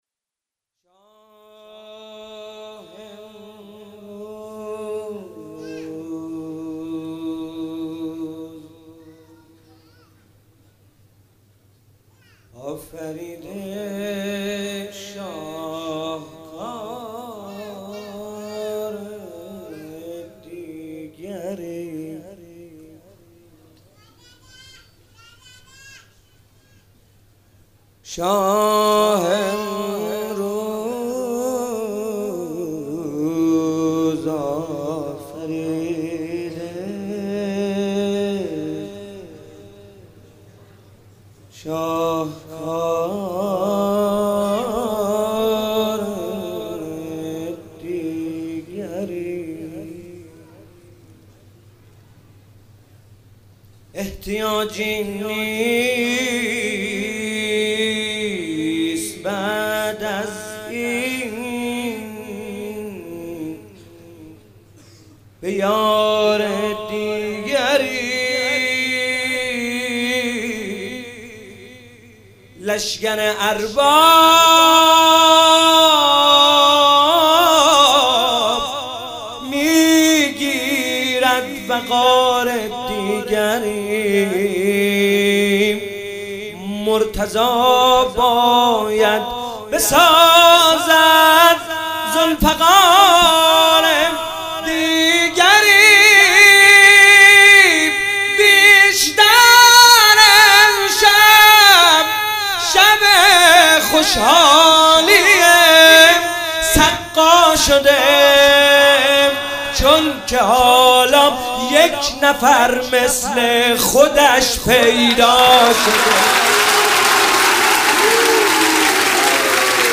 قالب : مدح